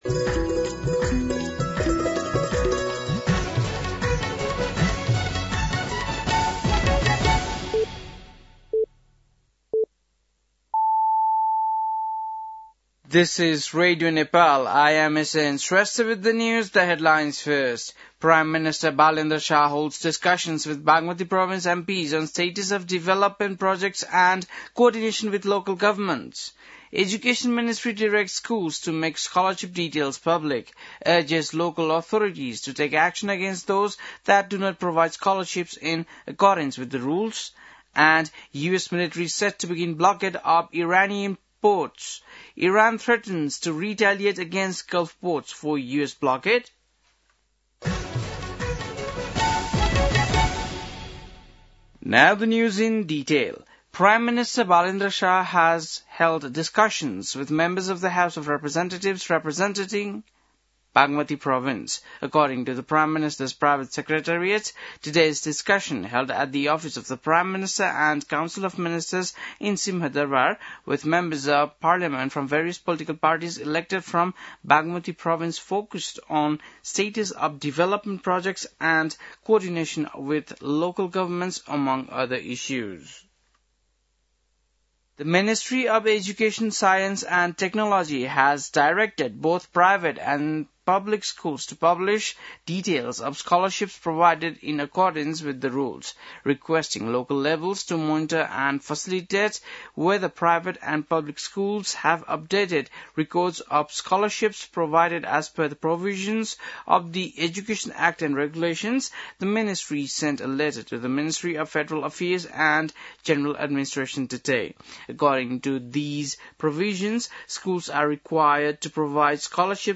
बेलुकी ८ बजेको अङ्ग्रेजी समाचार : ३० चैत , २०८२
8-PM-English-NEWS-12-30.mp3